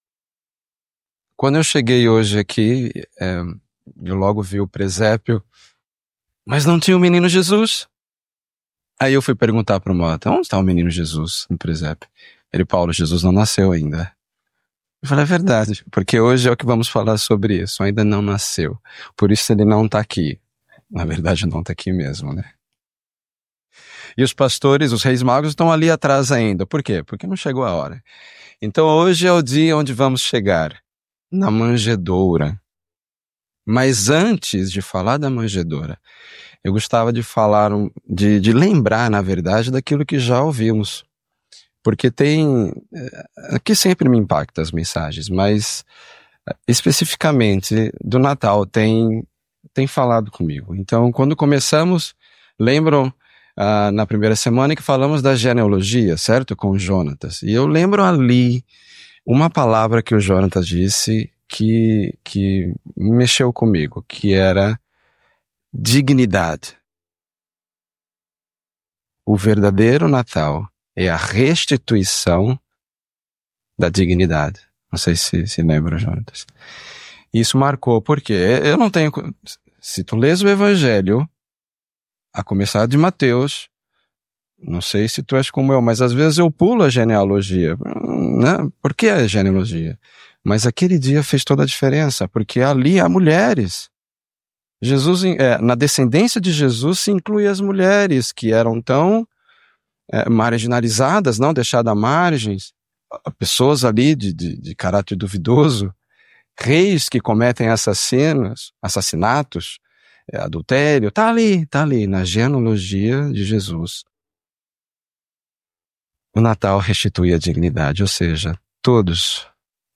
mensagem bíblica Lucas, no seu evangelho, diz que na pequena cidade de Belém não havia lugar para eles.
Devocional